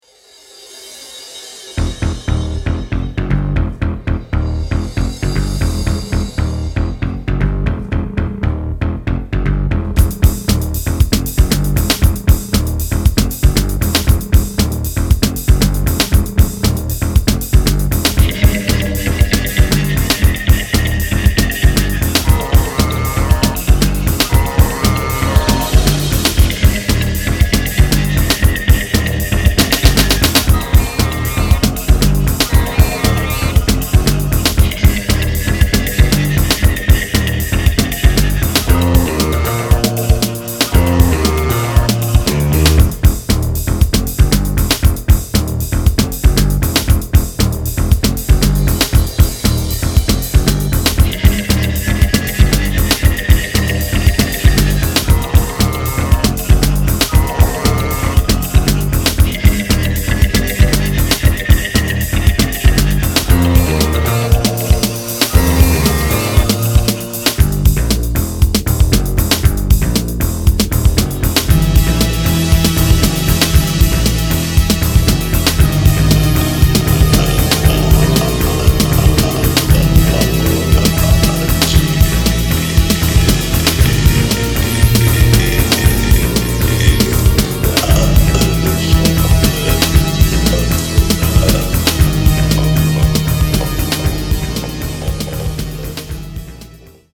サウンドスケープ　　霊性　呪術　フォーク